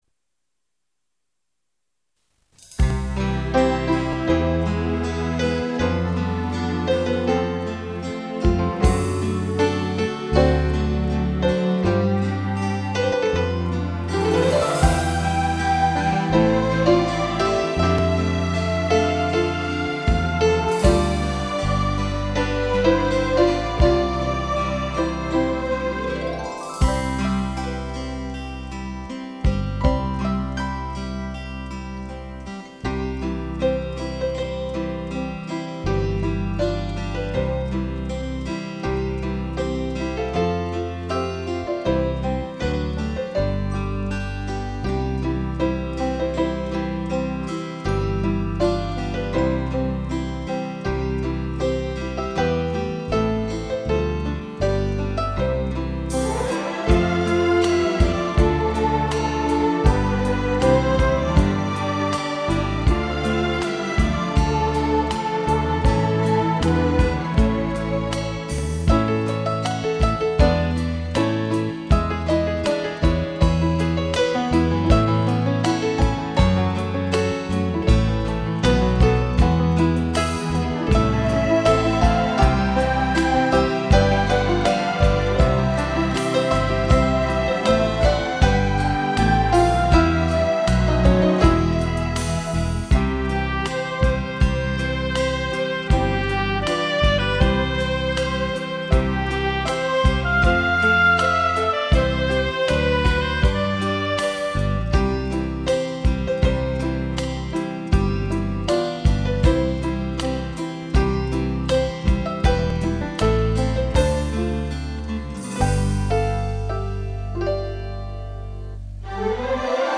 音质弱了点
虽然音质不太好，但这种轻音乐版本还是挺好听的